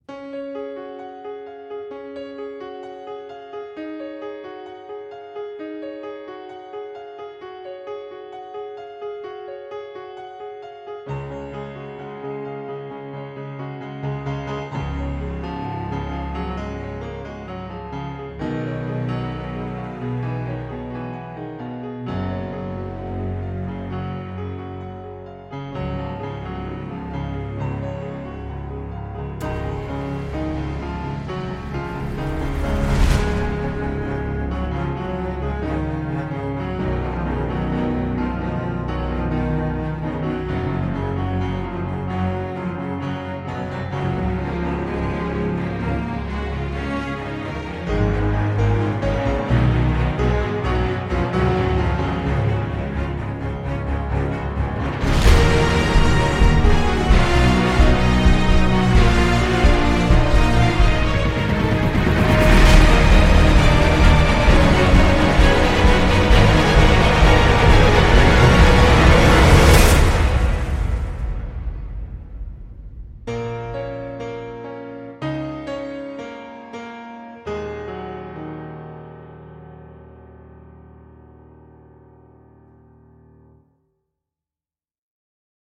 en ajoutant trop de nappes d’ambiance et d’électro.